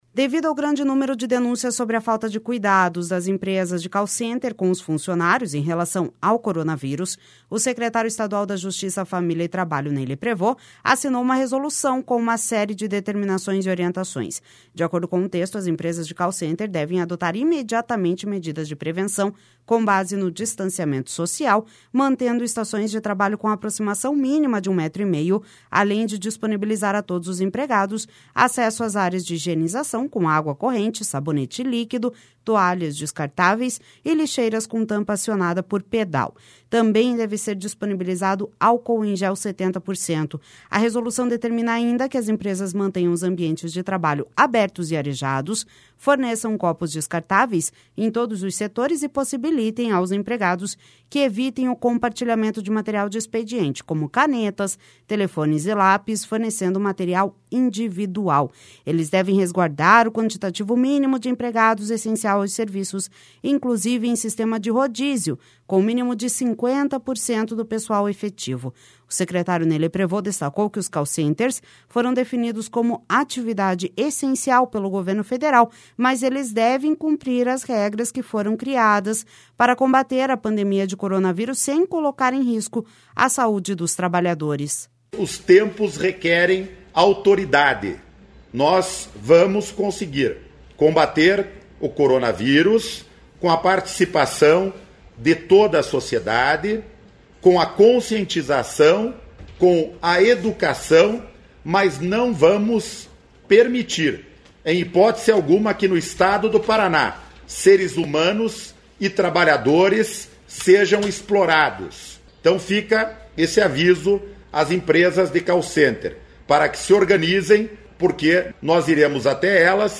O secretário Ney Leprevost destacou que os call centers foram definidos como atividade essencial pelo governo federal, mas eles devem cumprir as regras que foram criadas para combater a pandemia de coronavírus sem colocar em risco a saúde dos trabalhadores.// SONORA NEY LEPREVOST//A Secretaria, junto com o Ministério Público do Trabalho, a Secretaria Regional do Trabalho do Ministério da Economia, o Ministério Público Estadual do Paraná, a Secretaria da Saúde, a Secretaria Regional do Trabalho do Ministério da Economia e a Vigilância Sanitária Municipal, definem as ações de fiscalização nas empresas.